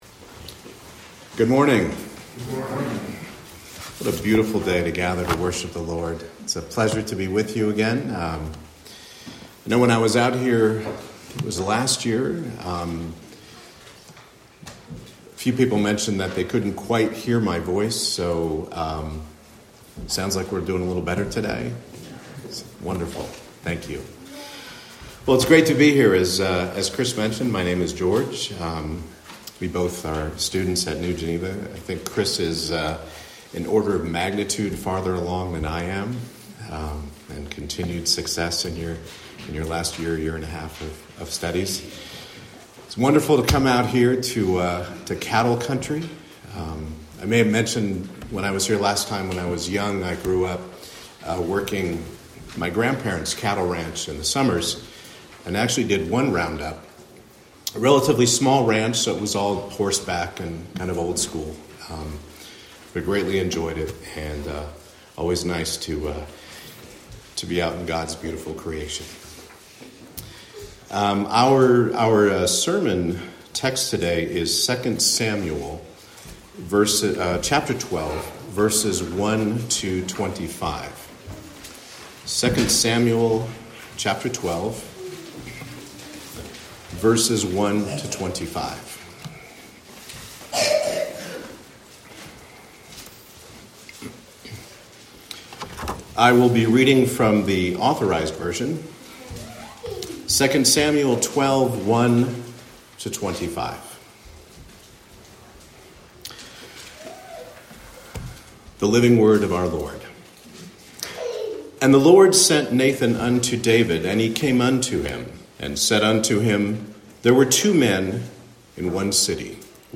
Guest Preacher Passage: 2 Samuel 12:1-25 Service Type: Morning Service David the king was confronted with the word of God through Nathan to repent.